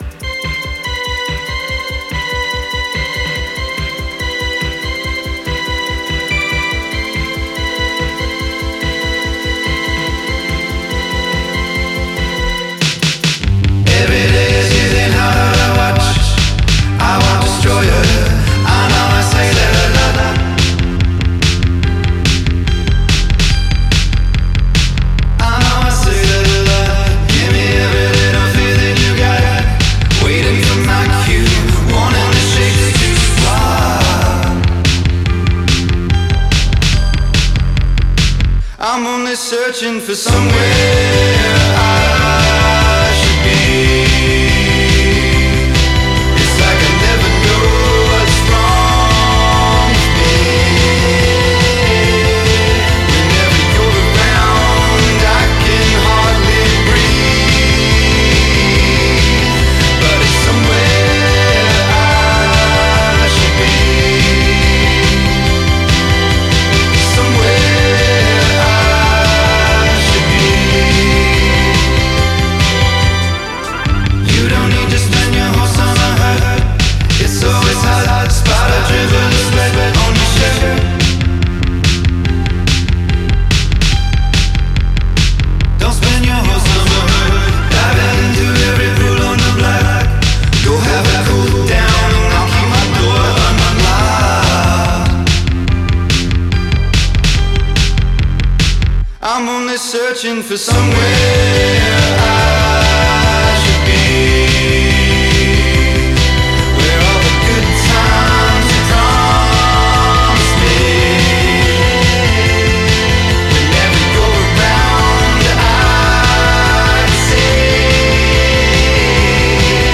an indie-tinged electropop bop
designed for dancing it out